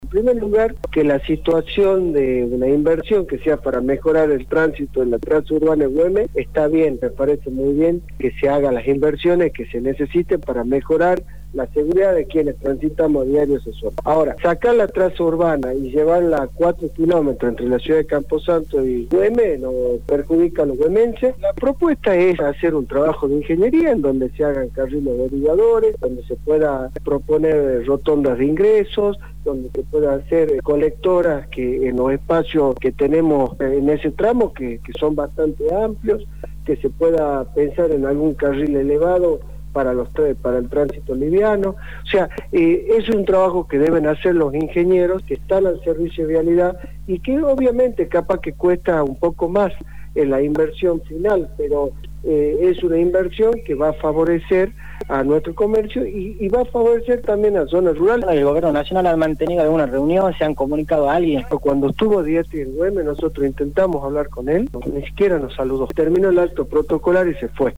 El Diputado Provincial por General Güemes, Germán Rallé habló sobre las propuestas que llevará a la mesa de trabajo por la construcción de la Ruta N°34.